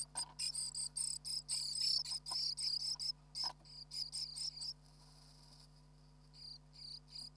波のようにビブラートのかかった波状音や小刻みに震えた音 で、音の高低、長さ、組み合わせの違いなどがある。
【営巣中のメスとオスのやりとり】
オスメスとも波状の音だが音程や波の周波数は大きく違っていた。